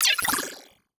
Space UI Touch and Reaction 5.wav